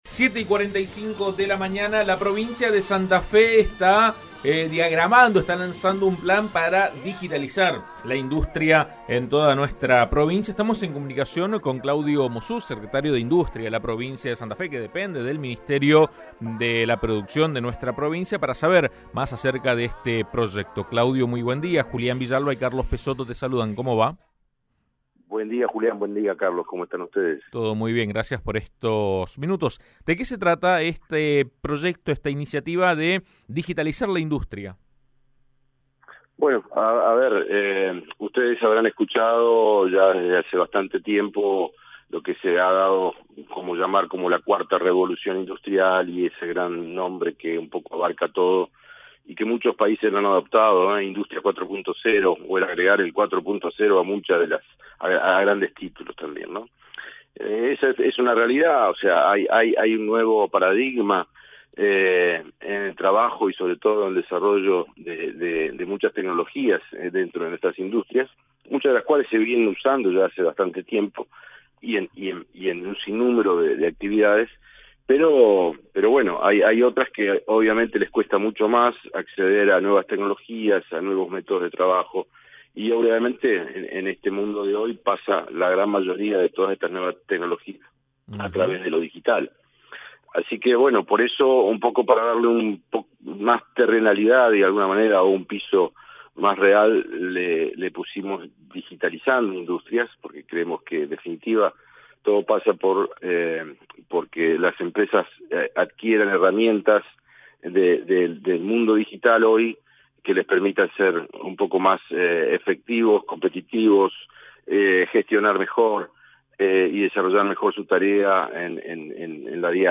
La federación de industriales de Santa Fe -FISFE- y el gobierno provincial lanzaron un plan para digitalizar la industria santafesina. AM 1330 dialogó al respecto con el secretario de industria del ministerio de producción, Claudio Mossuz.